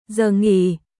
giờ nghỉ休憩時間ザー ンギー